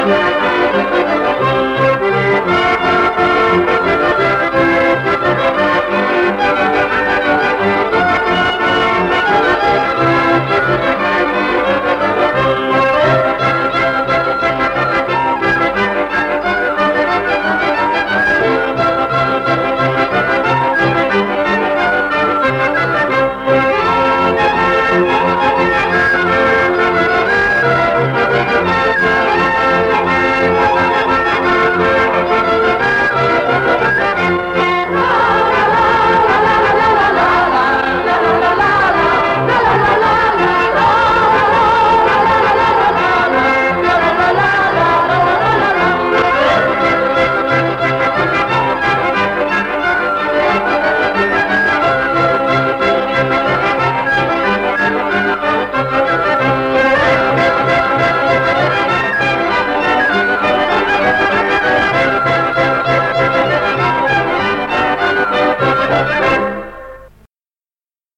Nagranie archiwalne